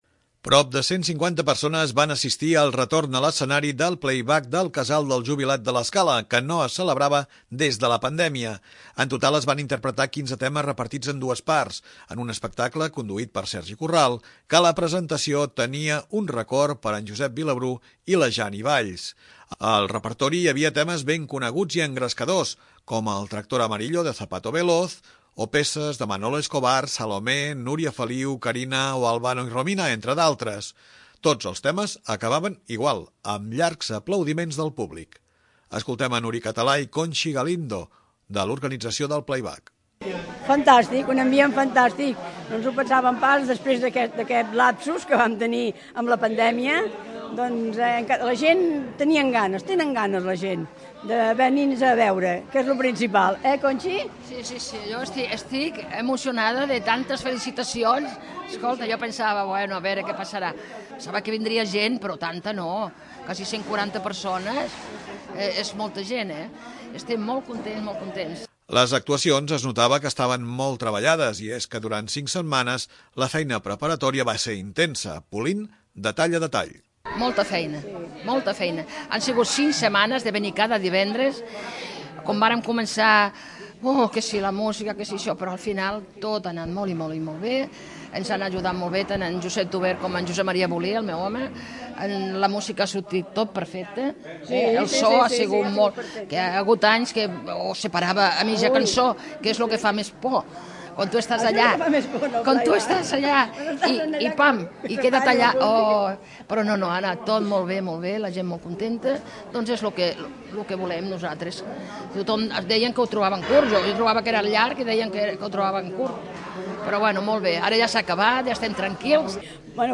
Llargs aplaudiments pels artistes que van participar del retorn del PlayBack del Casal del Jubilat. L'activitat tornava després de la pandèmia, i vist l'èxit, ja es pensa en l'edició de l'any vinent.